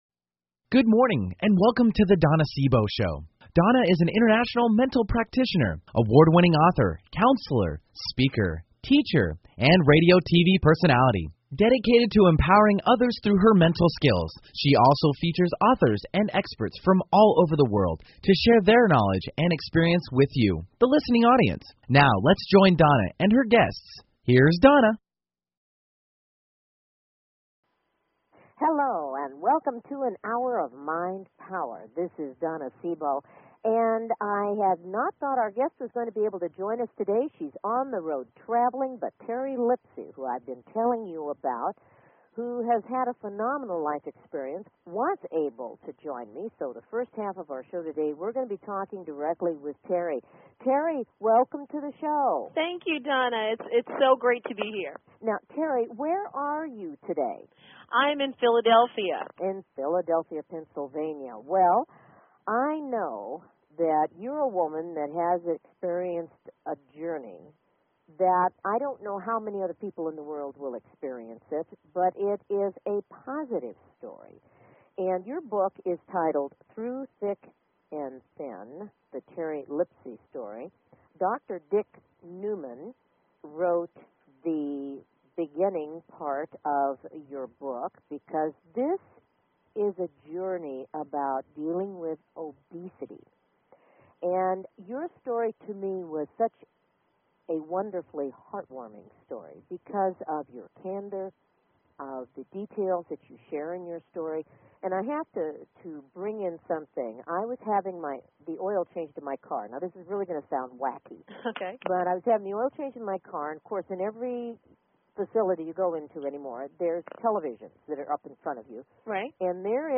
Talk Show Episode
Open Mike.
Callers are welcome to call in for a live on air psychic reading during the second half hour of each show.